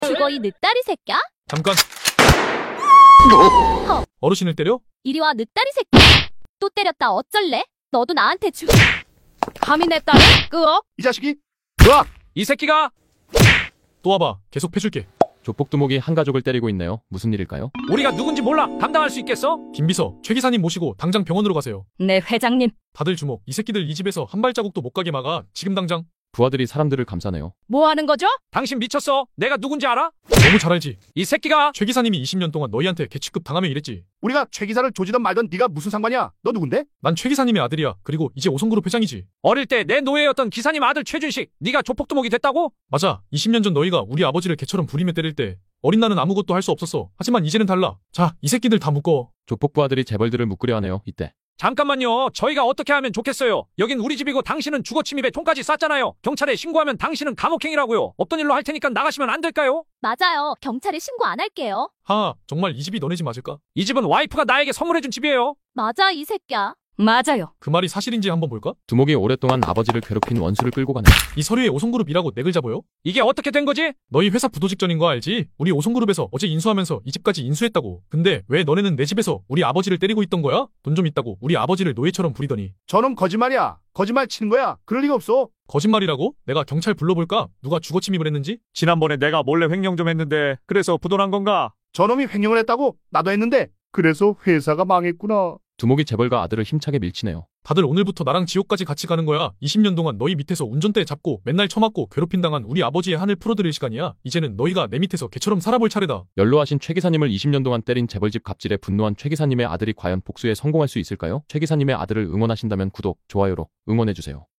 조폭 권총 sound effects free download